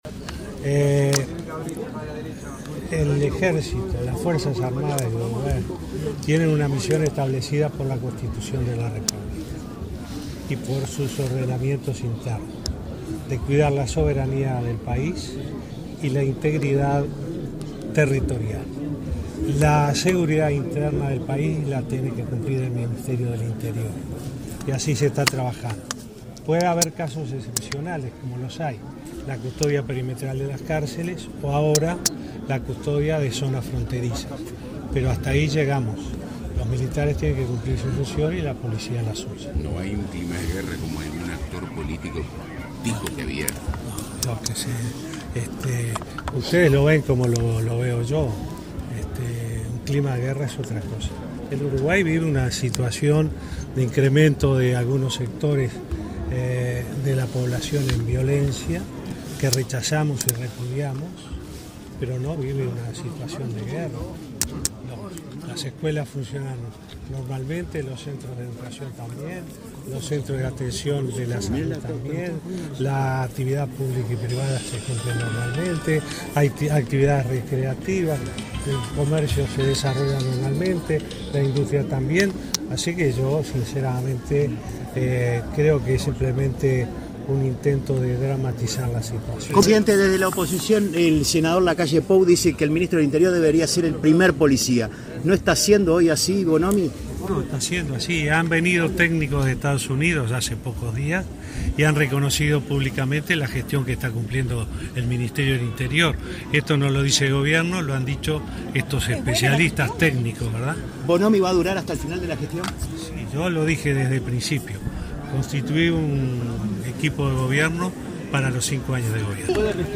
El presidente Vázquez destacó la gestión del Ministerio del Interior, respaldó al ministro Eduardo Bonomi y rechazó la posibilidad de sumar las Fuerzas Armadas a la tarea, al hablar con la prensa, previo al Consejo de Ministros.